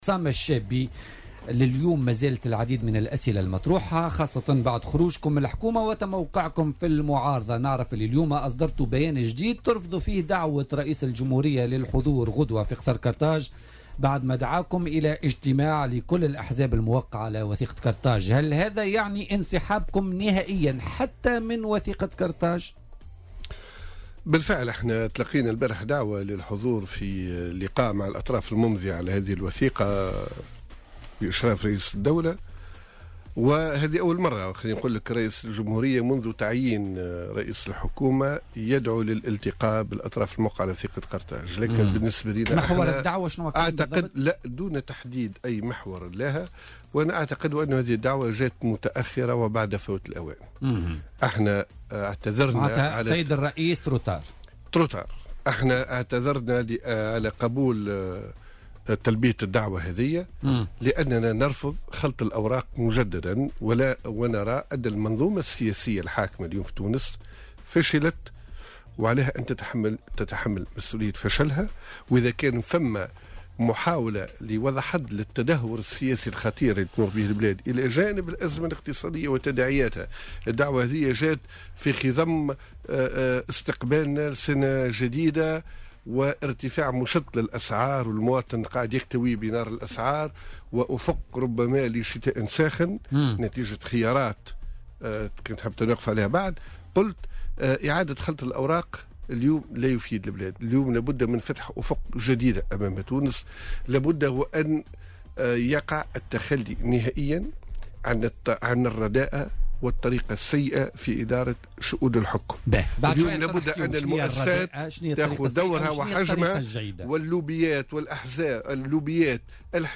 وأضاف الشابي، ضيف برنامج "بوليتيكا" اليوم الخميس أن "الجمهوري" اعتذر عن الحضور لأنه يرى أن هذه الوثيقة أفرغت من محتواها وأن هذه المنظومة السياسية الحاكمة فشلت وعليها تحمل مسؤولية فشلها، وفق تعبيره.